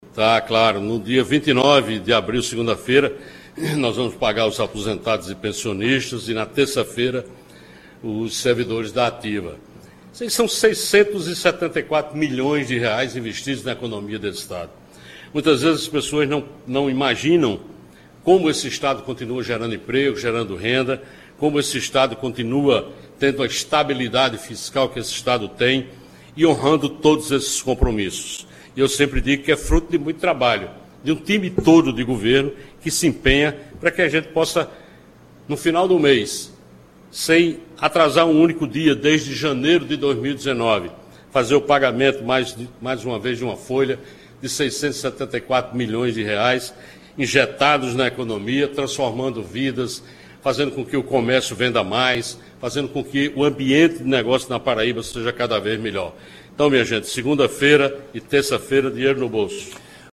O anúncio foi feito pelo governador João Azevêdo, nesta segunda-feira (22), durante o programa semanal Conversa com o Governador, transmitido em cadeia estadual pela Rádio Tabajara.